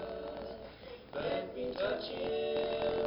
I have an audio recording that has a unwanted noise in it.
Your interference is only there during vocals.
I’m finding even using the show painful way of notching out interference isn’t working because the noise doesn’t stay at one pitch.
I think the recording was made with an Ipaq PDA…remember those?
This is a serious interruption of the actual audio data like the recorder can’t keep up.
It mostly goes away during silences.